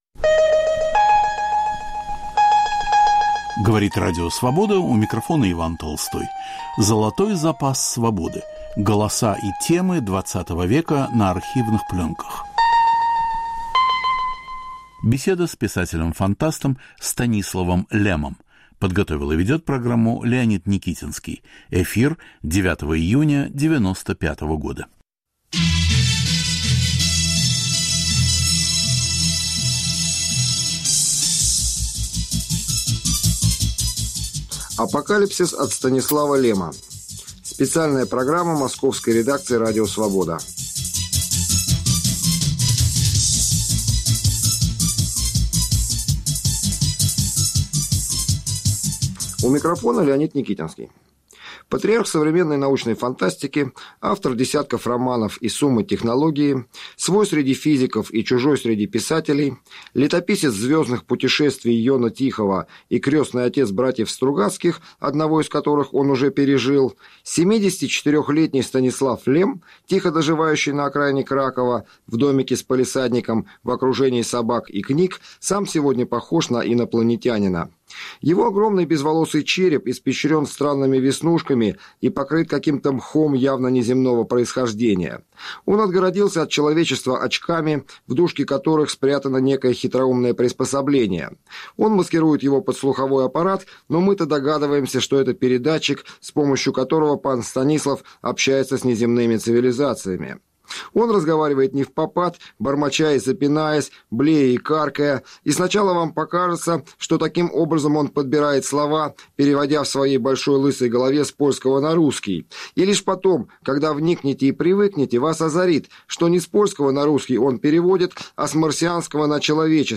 Голоса и темы XX века на архивных пленках. Апокалипсис от Станислава Лема. Что такое мода?